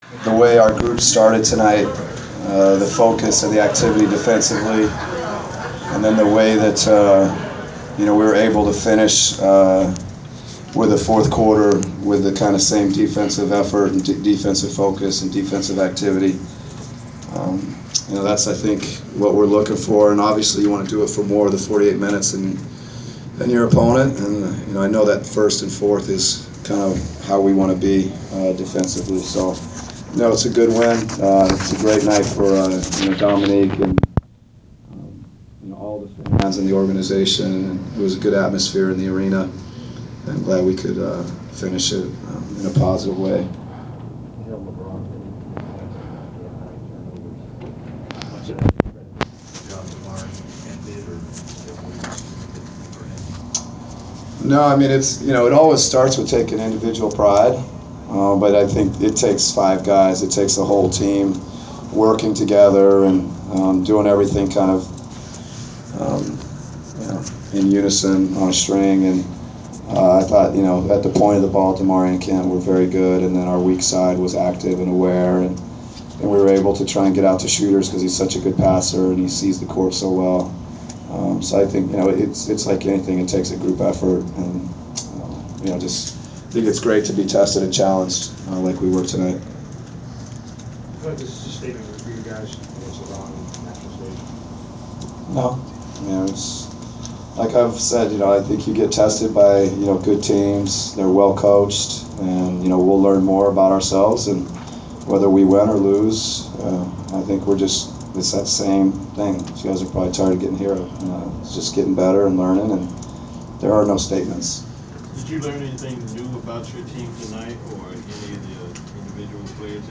Inside the Inquirer: Postgame presser with Atlanta Hawks head coach Mike Budenholzer (3/7/15)
We attended the postgame presser of Atlanta Hawks’ head coach Mike Budenholzer following his team’s 106-97 home win over the Cleveland Cavs on Mar. 6. Topics included the win, defending LeBron James and the execution of the offense.